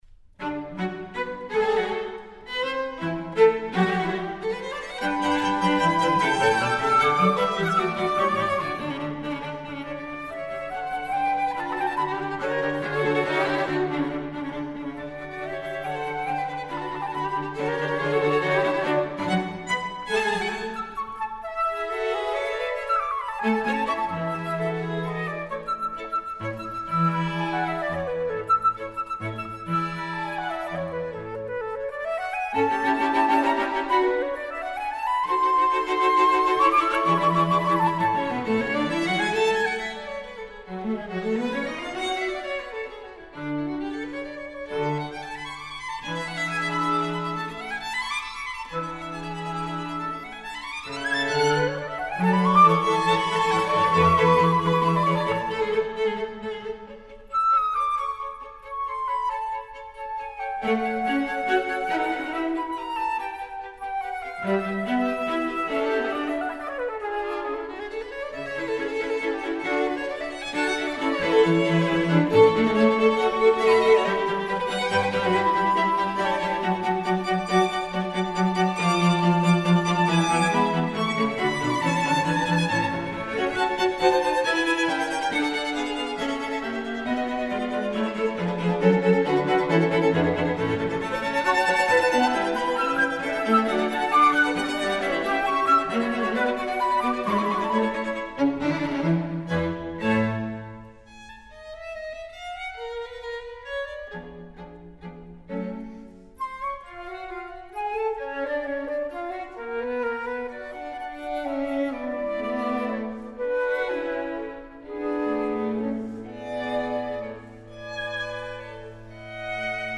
Quintet for flute and string quartet in D major op. 7.